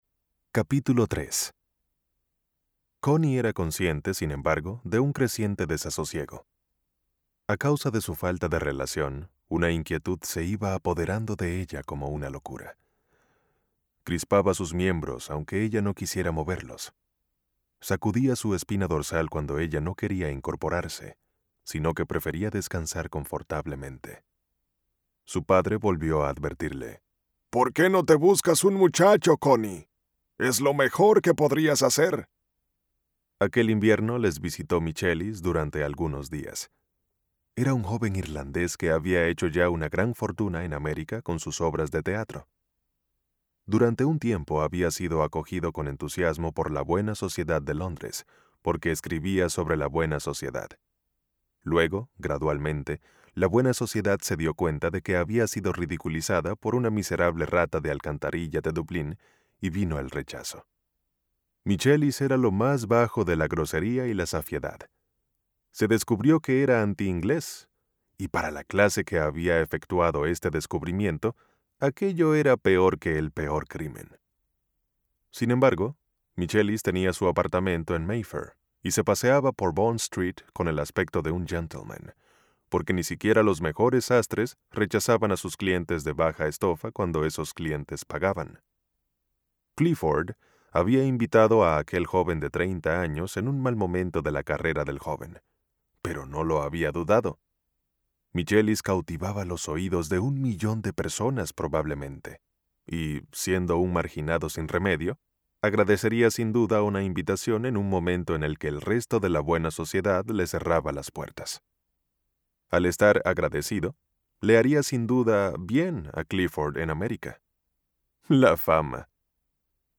Male
Confident, Corporate, Deep, Engaging, Upbeat, Versatile
Dominican Spanish (Native) Latin English (Accent) Neutral Latam Spanish (Native)
Microphone: Neumann TLM 103, Shure SM7dB
Acoustically treated studio.